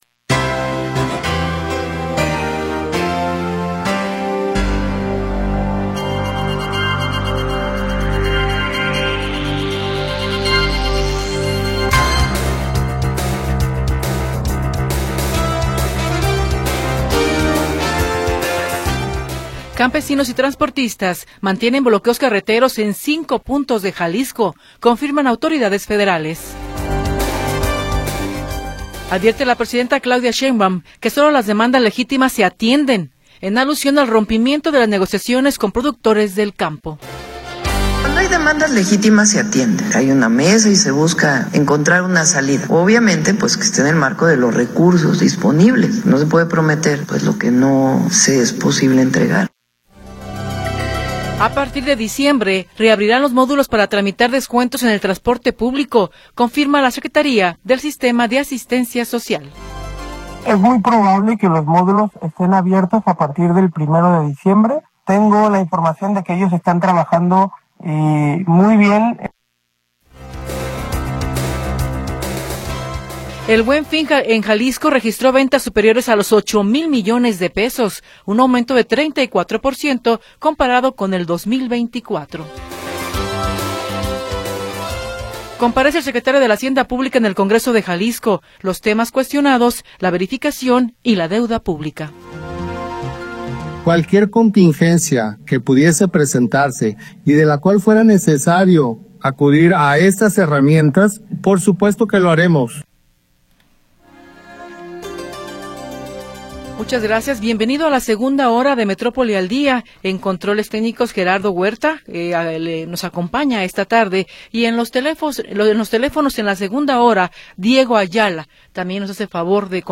La historia de las últimas horas y la información del momento. Análisis, comentarios y entrevistas